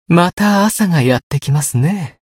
觉醒语音 また朝がやって来ますね 媒体文件:missionchara_voice_720.mp3